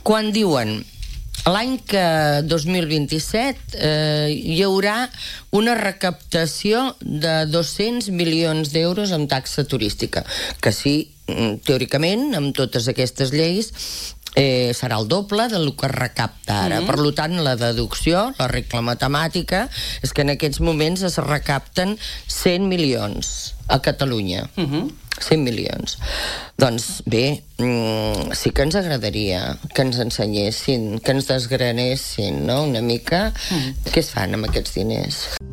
Ho ha explicat aquest dimecres en una entrevista al programa matinal de RCT, després de la visita del Gremi a la fira turística de Madrid.